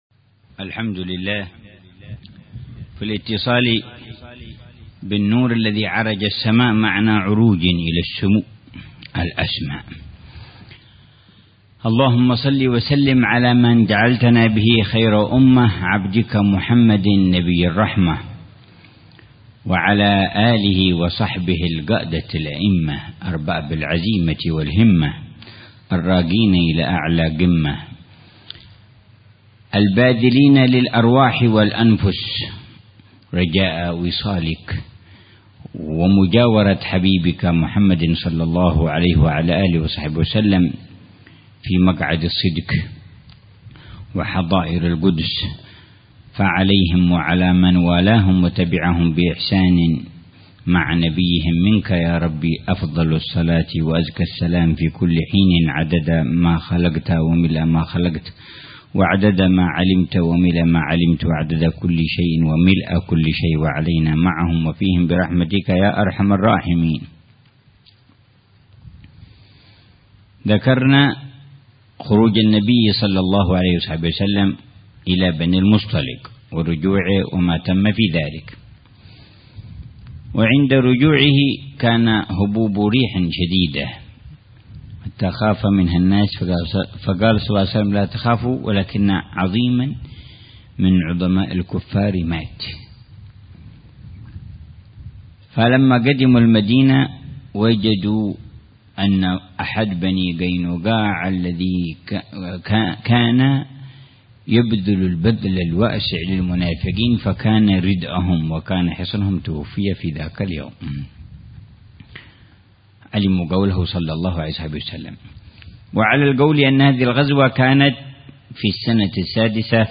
جولان الروح - الدرس الثاني والأربعون - صلح الحديبية.. (1)